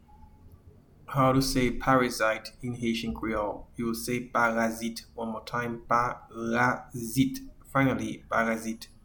Pronunciation:
Parasite-in-Haitian-Creole-Parazit.mp3